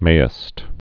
(māĭst) or mayst (māst)